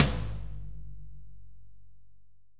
Index of /90_sSampleCDs/AKAI S6000 CD-ROM - Volume 3/Drum_Kit/AMBIENCE_KIT3
R.AMBTOMF2-S.WAV